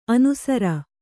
♪ anusara